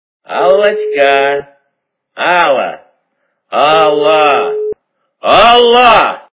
Именные звонки